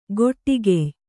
♪ goṭṭigey